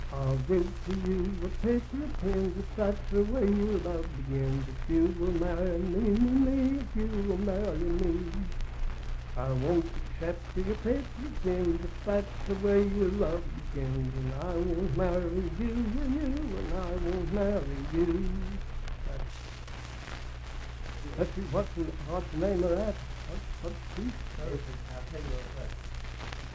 Unaccompanied vocal music
in Mount Storm, W.V.
Verse-refrain 2(4).
Children's Songs, Dance, Game, and Party Songs
Voice (sung)